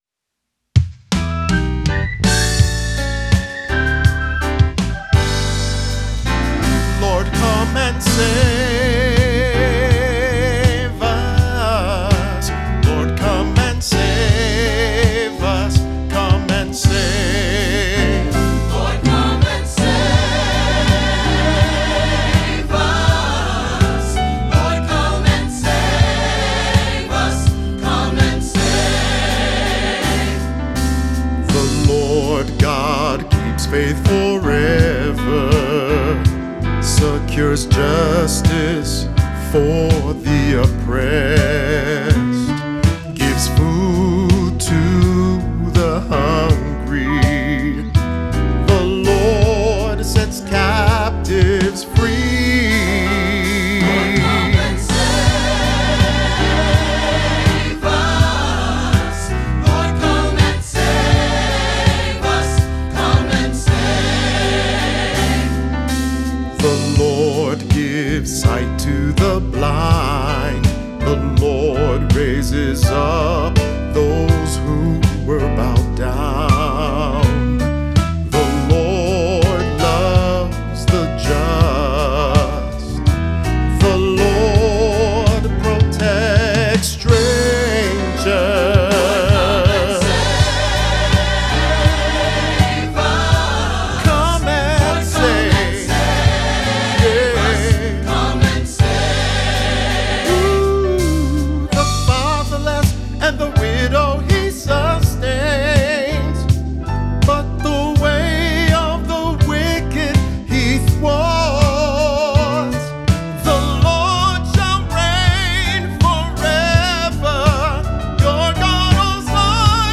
Voicing: "SATB","Cantor","Assembly"